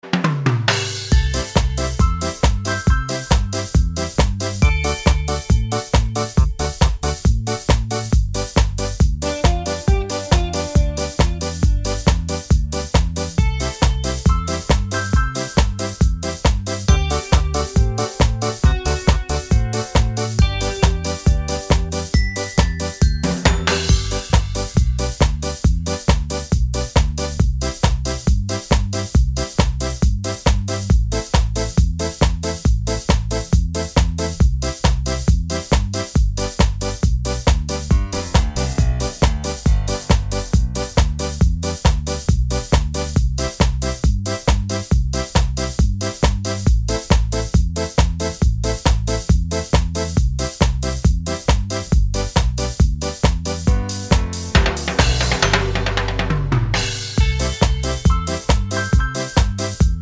no Backing Vocals Ska 4:27 Buy £1.50